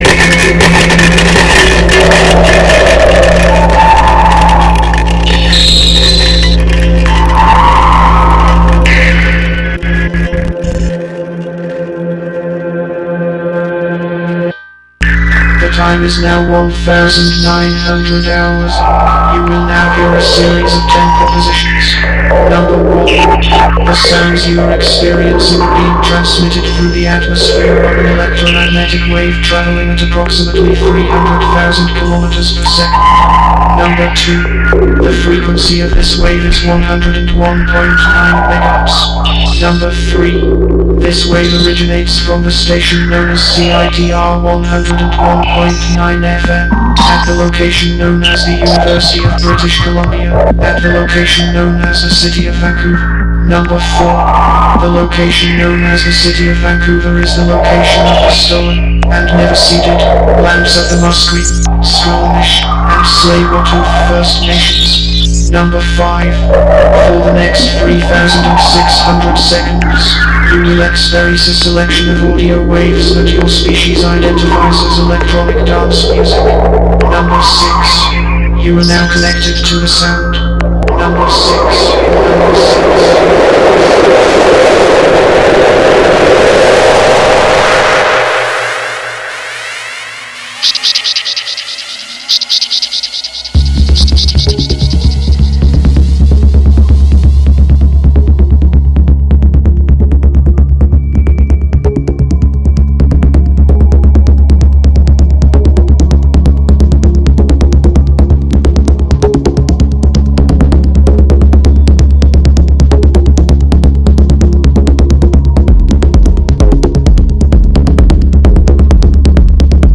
i am a born again techno convert!